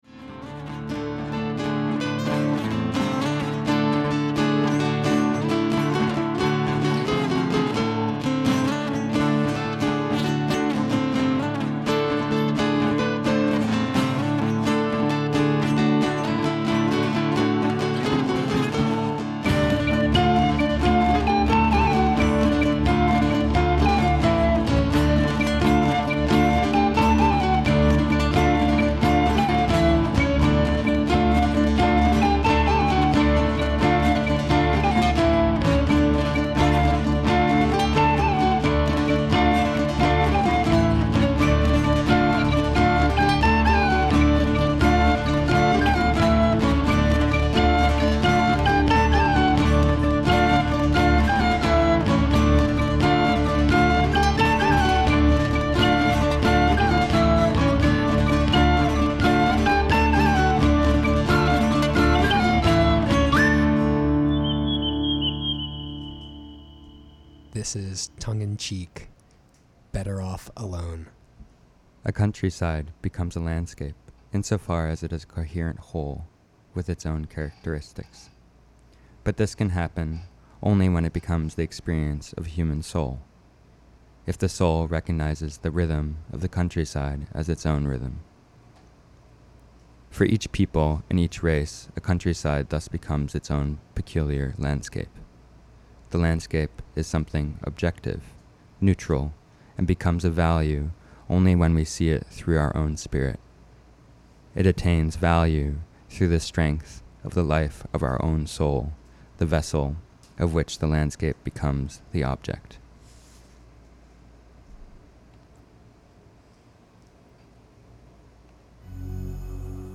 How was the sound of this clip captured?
(Socialise) First broadcast on Montez Press Radio as Tongue and Cheek- Ep10 Better off alone?- Friday, June 28, 2019.